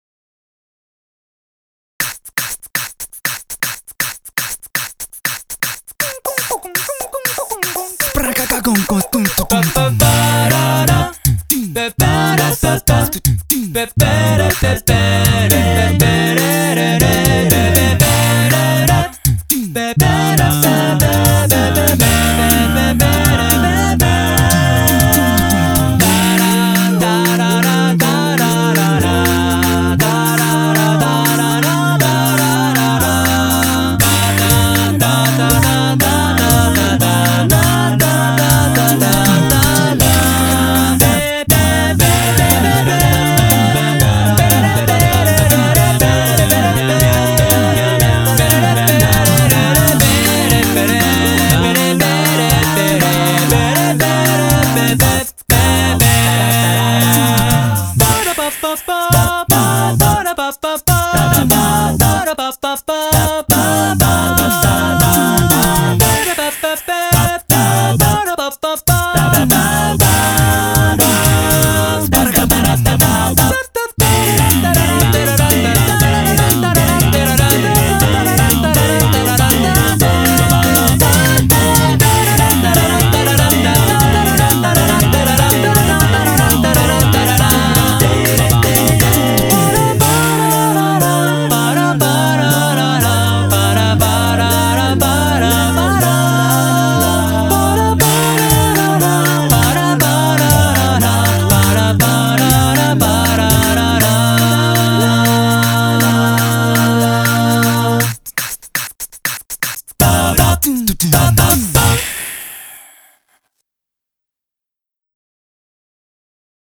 BPM120
Audio QualityPerfect (High Quality)
This is because it only has male vocals.